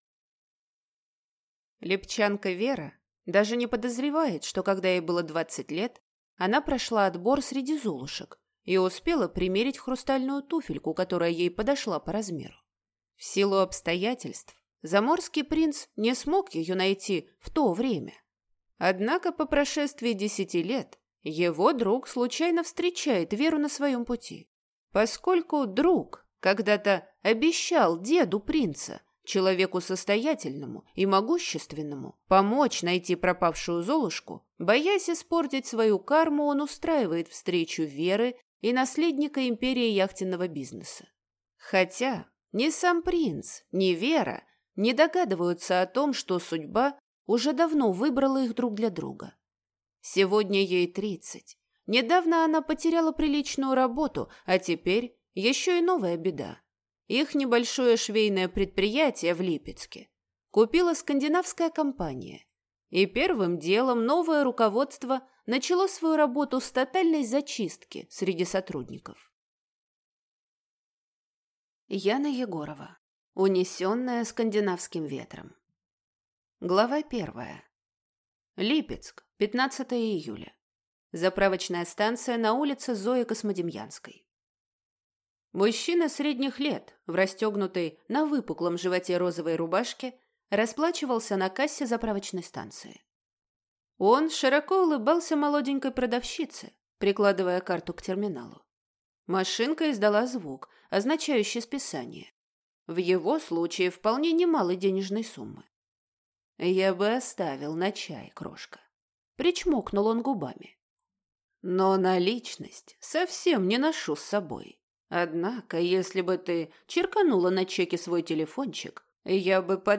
Аудиокнига Унесенная скандинавским ветром | Библиотека аудиокниг